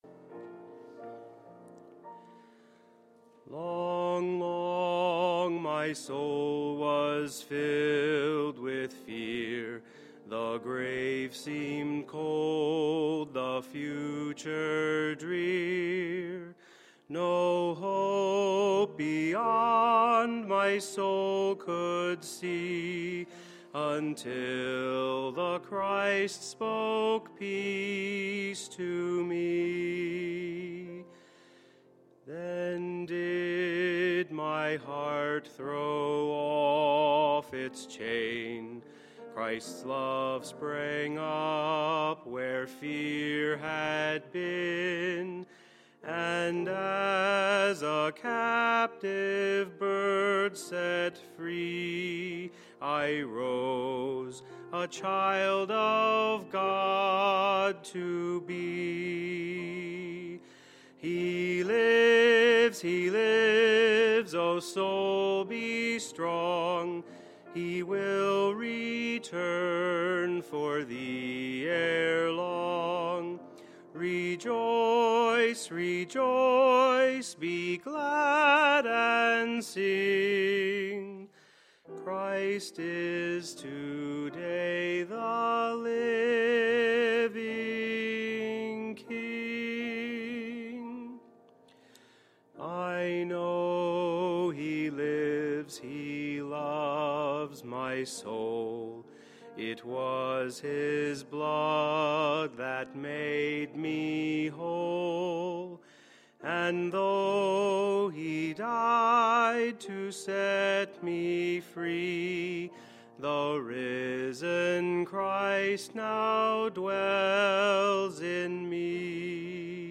Sunday, March 31, 2013 – Resurrection Service